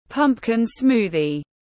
Sinh tố bí đỏ tiếng anh gọi là pumpkin smoothie, phiên âm tiếng anh đọc là /ˈpʌmp.kɪn ˈsmuː.ði/
Pumpkin smoothie /ˈpʌmp.kɪn ˈsmuː.ði/